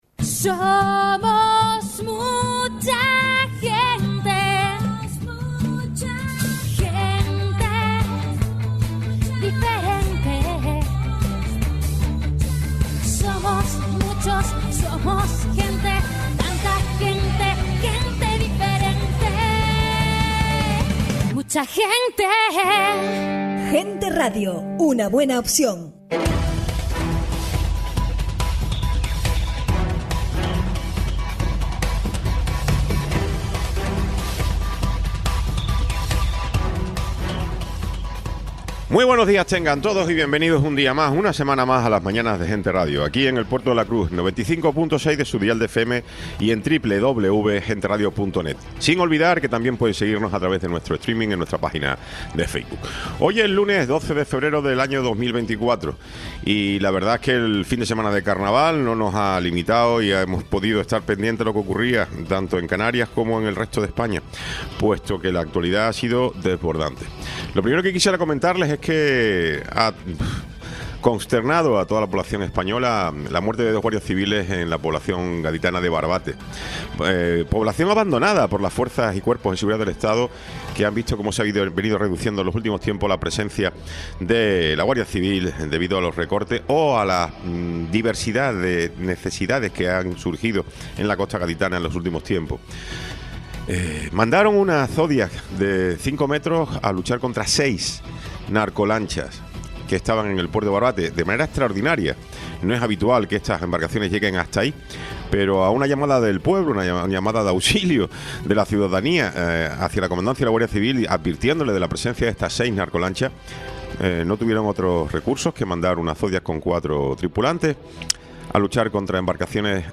Tertulia
Programa sin cortes